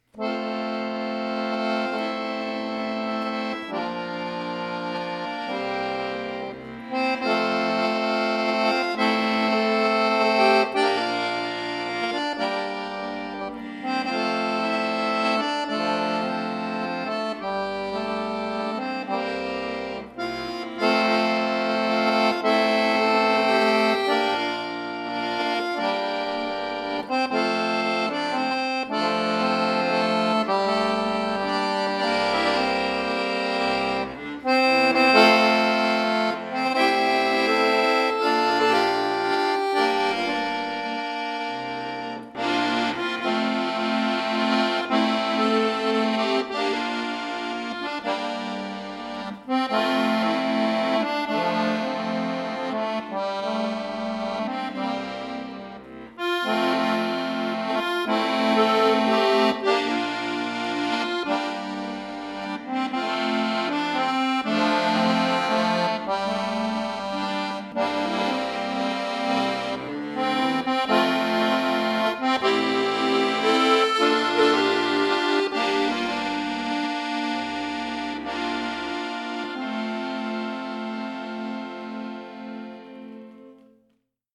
Alte schottische Ballade, neu arrangiert für Akkordeon solo